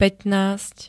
Zvukové nahrávky niektorých slov